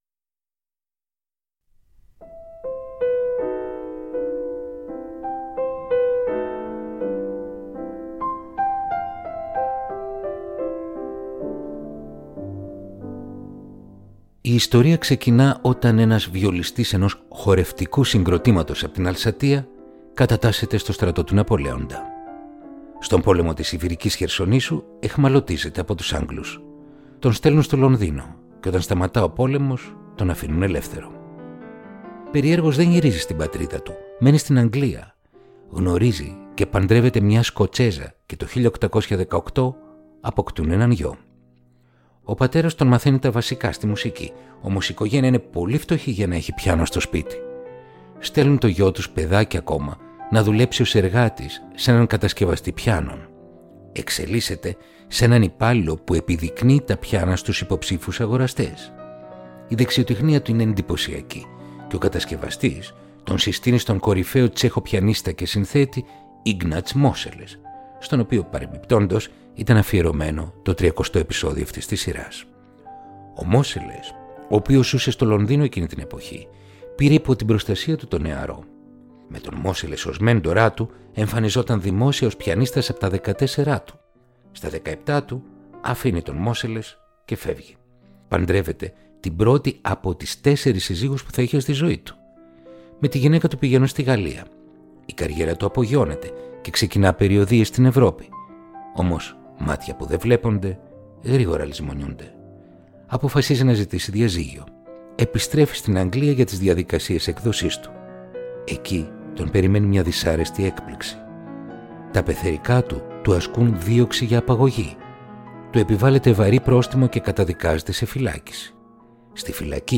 Ρομαντικά κοντσέρτα για πιάνο – Επεισόδιο 33ο